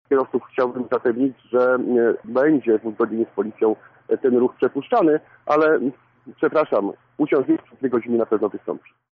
Akcja spowoduje pewne utrudnienia w ruchu – mówi Wojciech Żukowski, burmistrz Tomaszowa: